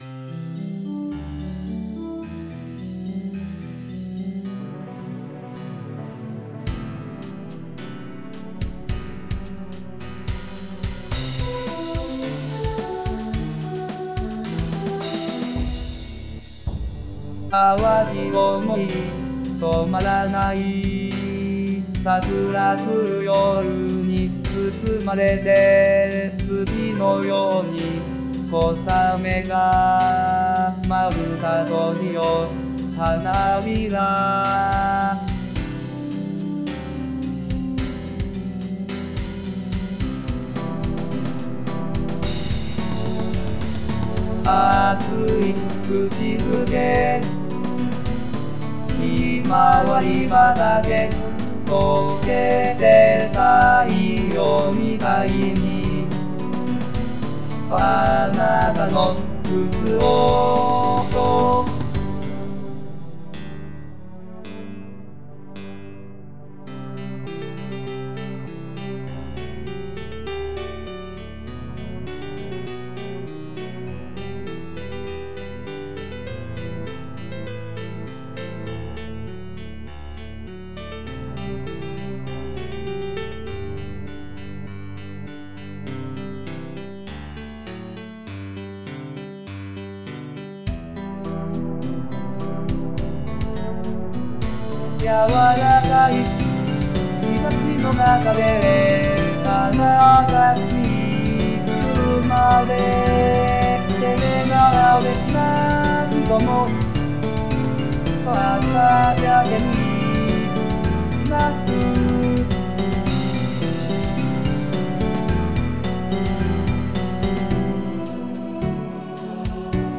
二重唱